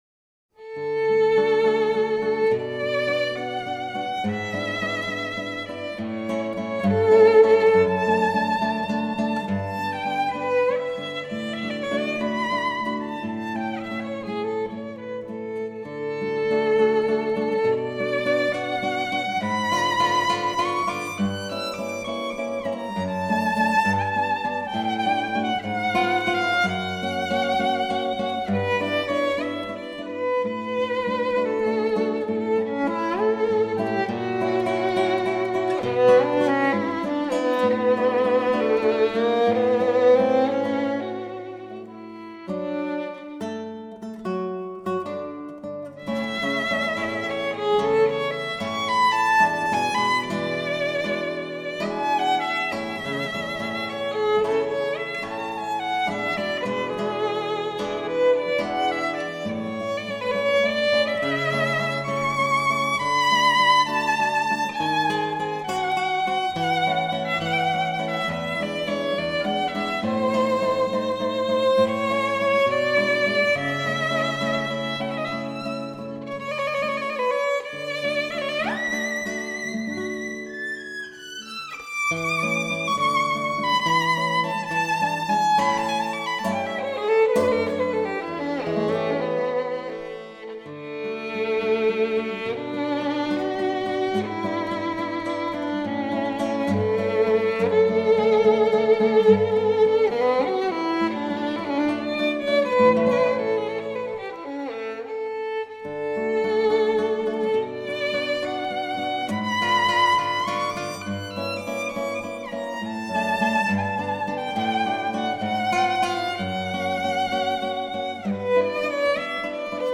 violino
chitarra.